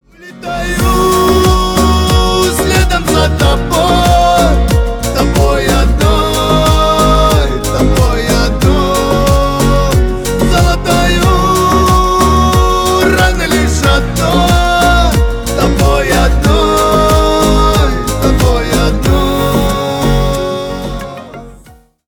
• Качество: 320 kbps, Stereo
Поп Музыка
грустные
кавказские